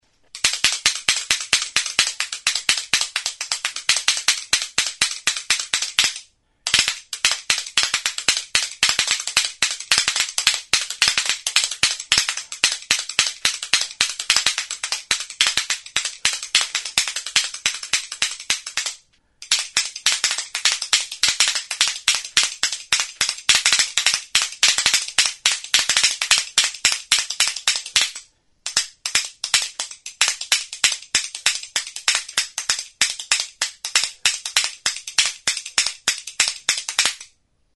Music instrumentsKALAKA
Idiophones -> Struck -> Indirectly
Recorded with this music instrument.
Astintzerakoan palek elkarri jota hotsa ematen du.